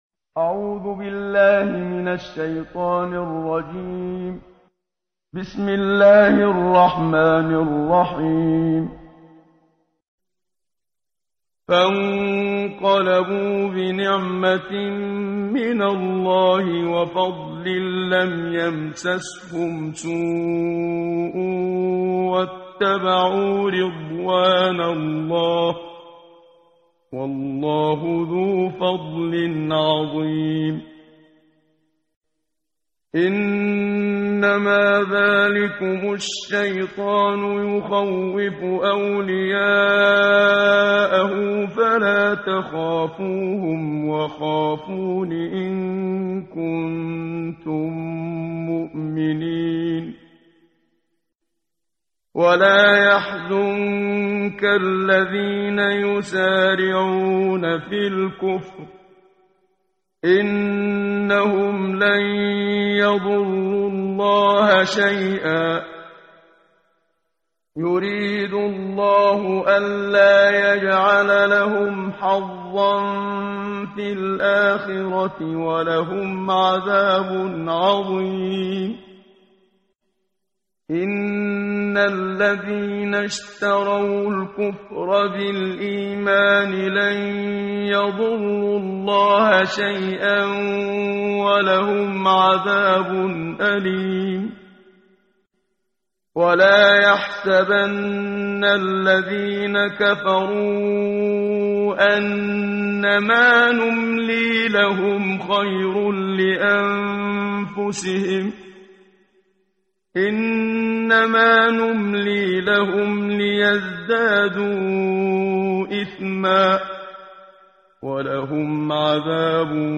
قرائت قرآن کریم ، صفحه 73، سوره مبارکه آلِ عِمرَان آیه 174 تا 180 با صدای استاد صدیق منشاوی.